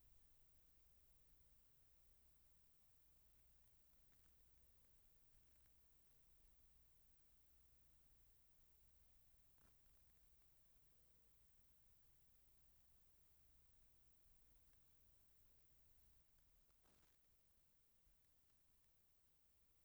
The two main culprits are this static sound and the high pitched beeping.
My Blue Yeti is plugged right into my mac through the USB port.
The static goes in or out with no rhyme or reason as far as I can tell.